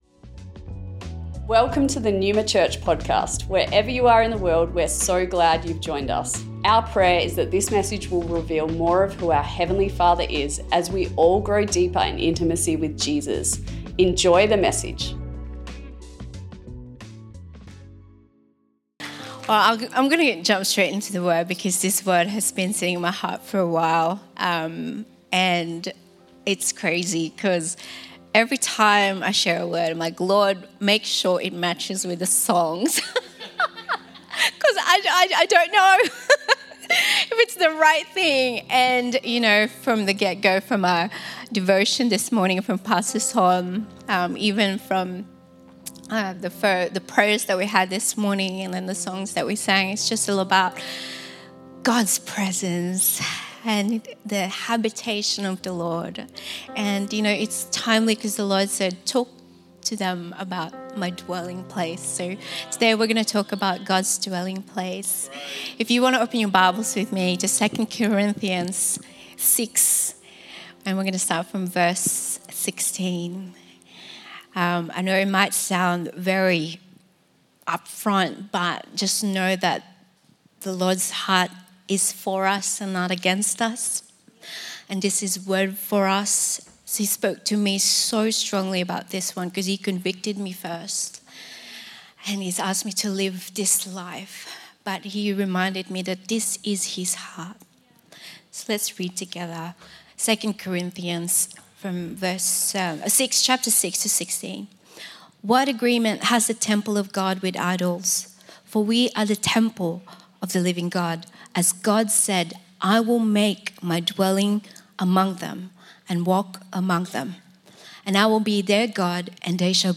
Recorded at Melbourne Neuma West